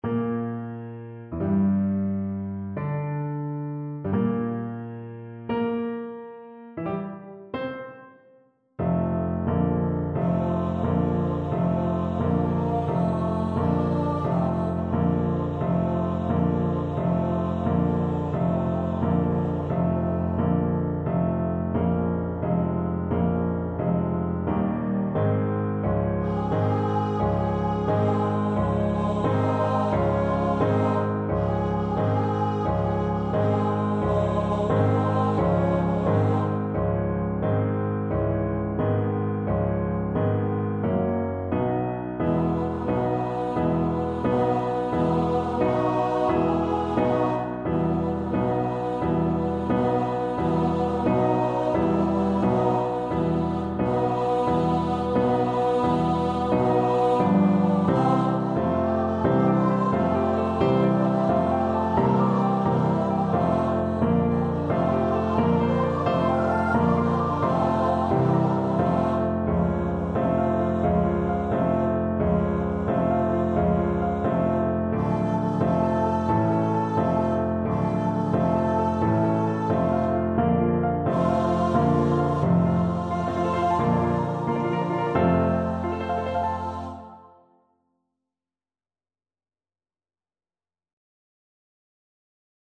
Choir  (View more Intermediate Choir Music)
Classical (View more Classical Choir Music)